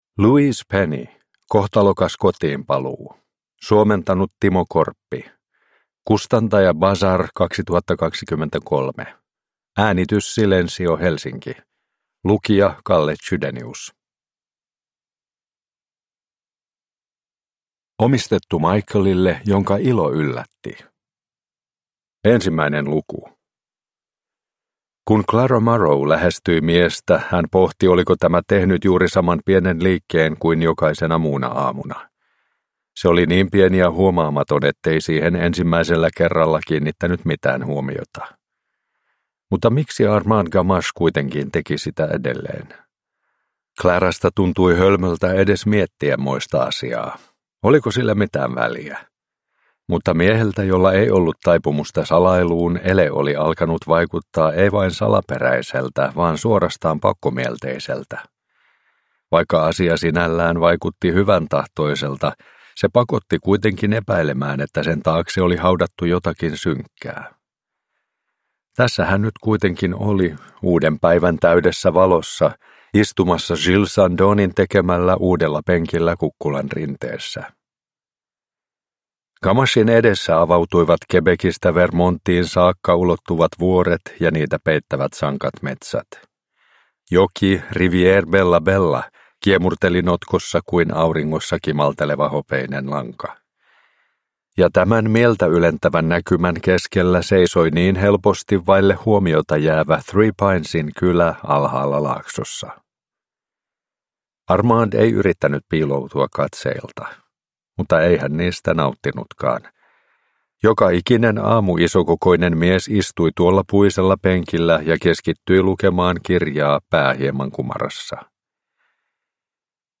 Kohtalokas kotiinpaluu – Ljudbok